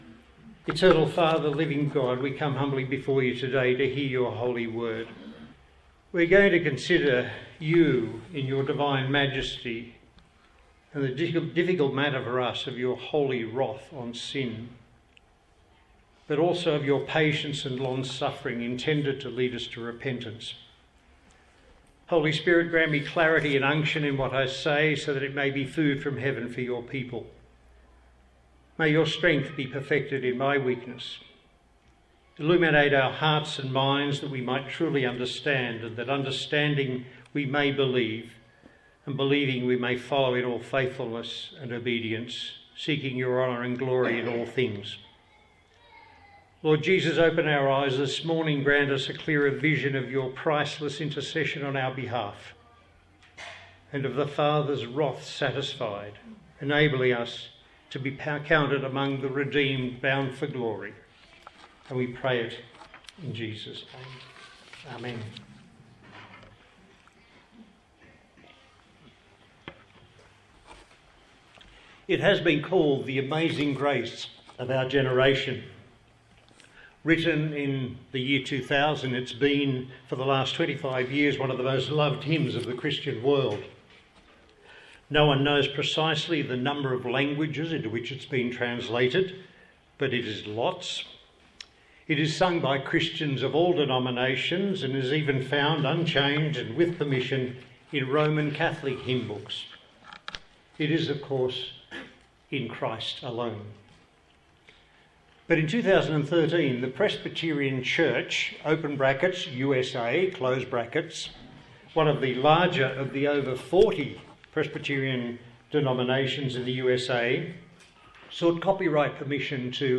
A sermon on the book of Nahum
Service Type: Sunday Morning